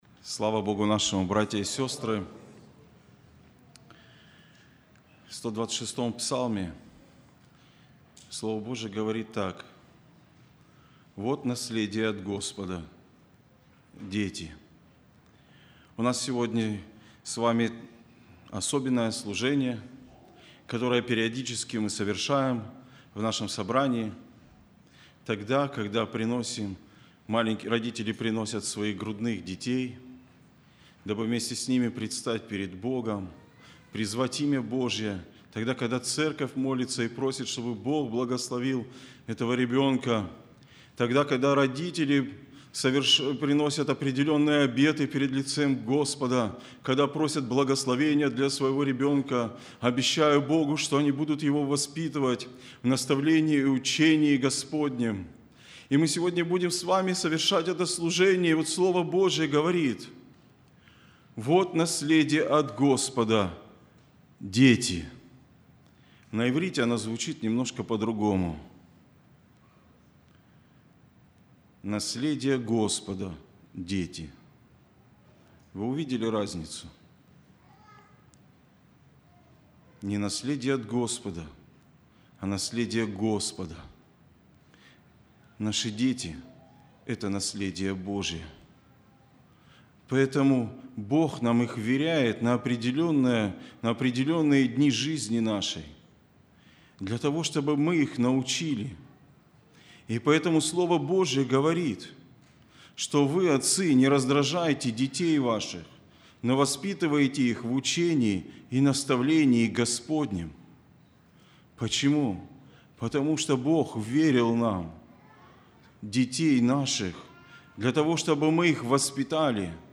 07-16-23 Воскресение — Церковь «Путь ко Спасению»
02+Проповедь.mp3